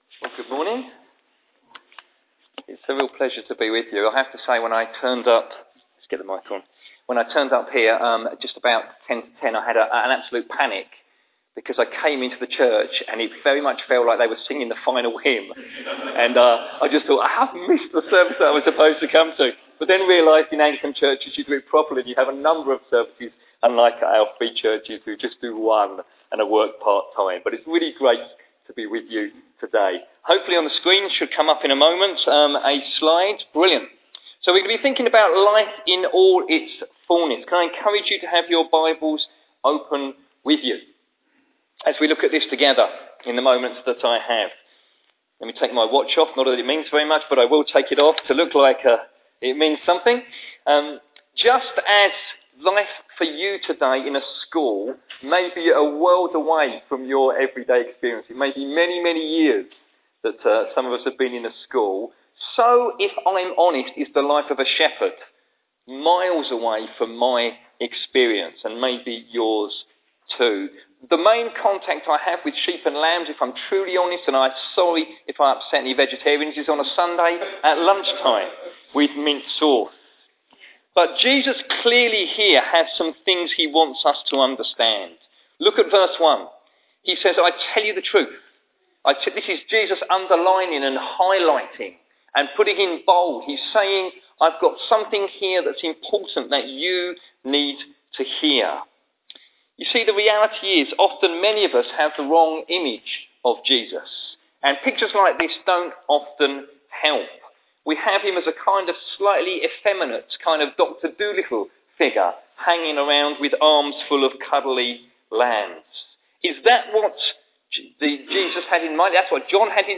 A sermon on John 10:1-11